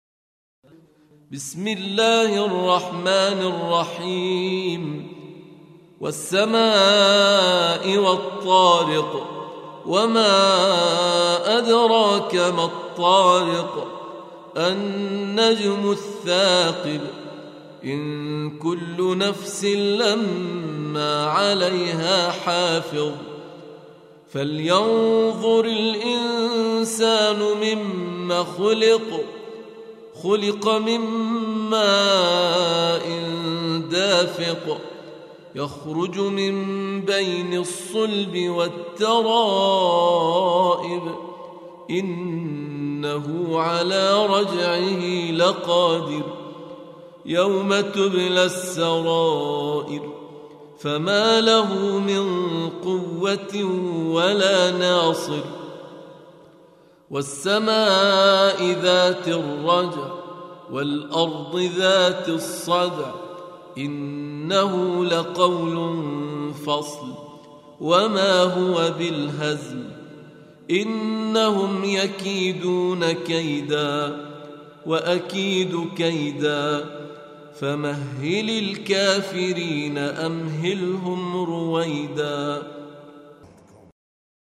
Audio Quran Tarteel Recitation
حفص عن عاصم Hafs for Assem
Surah Sequence تتابع السورة Download Surah حمّل السورة Reciting Murattalah Audio for 86. Surah At-T�riq سورة الطارق N.B *Surah Includes Al-Basmalah Reciters Sequents تتابع التلاوات Reciters Repeats تكرار التلاوات